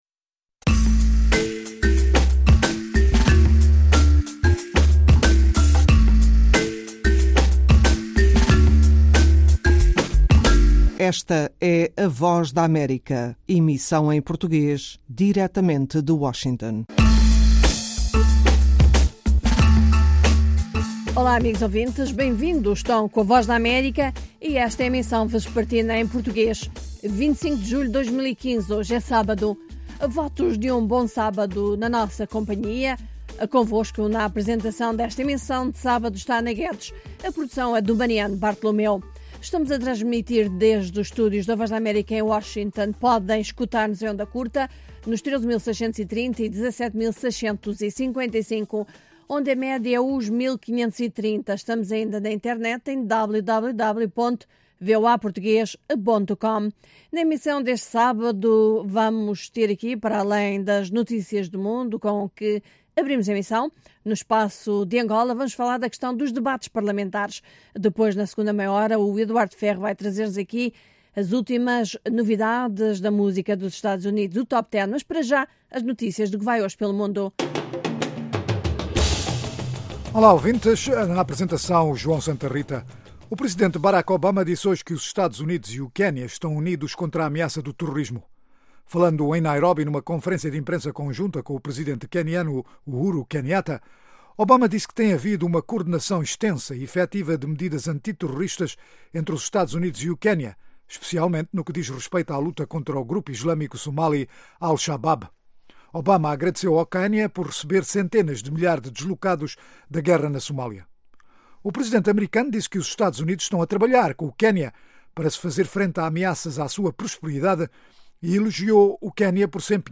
Meia-hora duas vezes por Aos sábados, ouça uma mesa redonda sobre um tema dominante da política angolana, música americana e as notícias do dia.